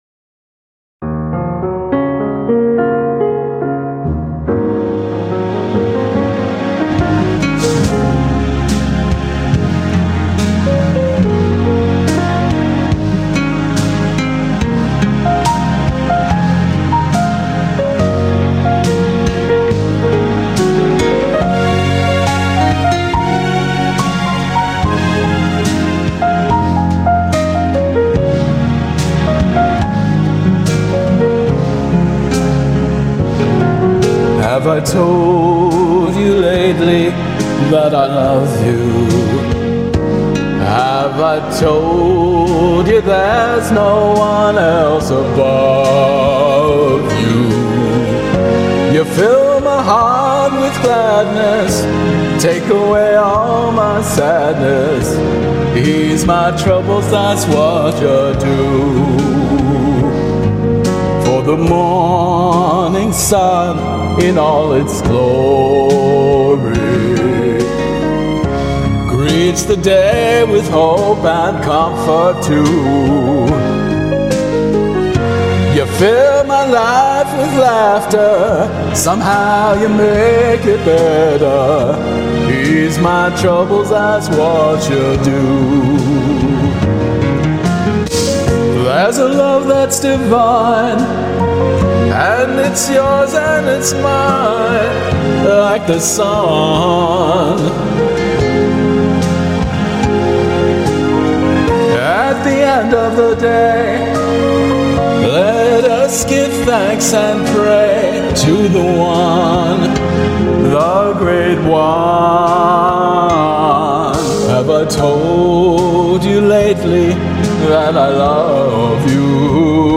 karaoke arrangement
A love song often played at weddings